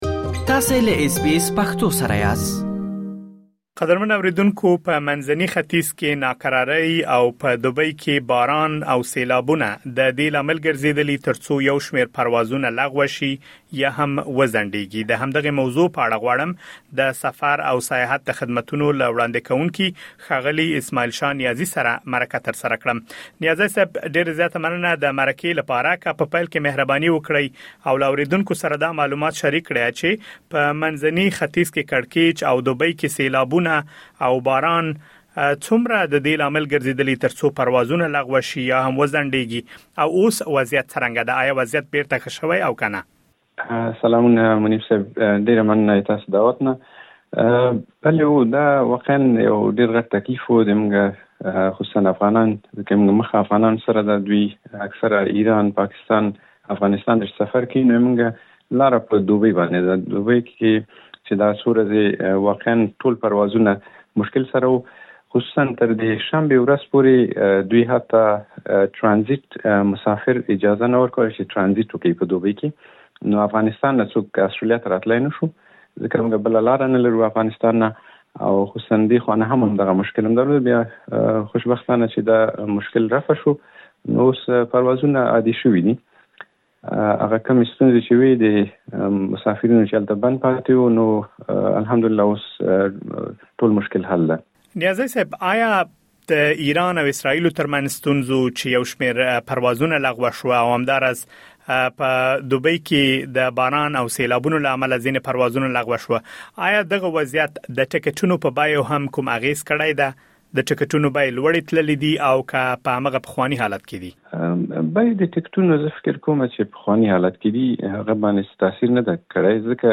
مرکه ترسره کړې